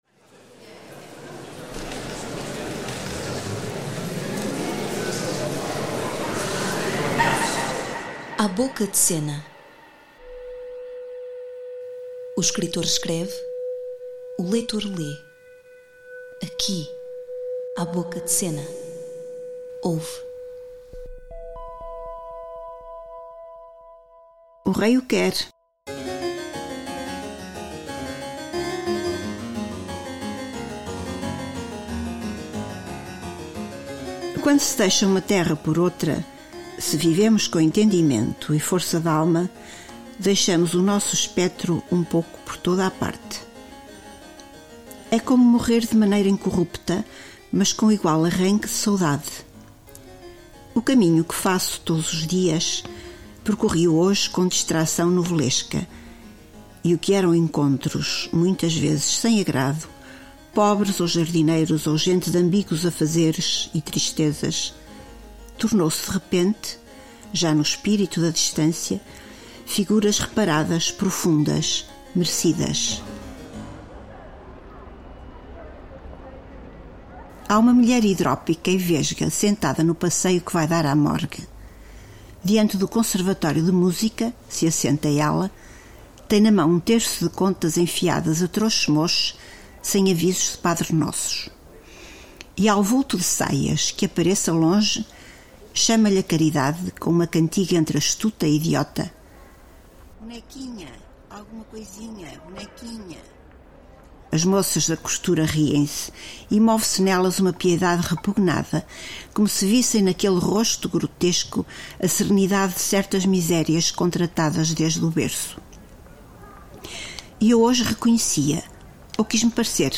Música – excertos de: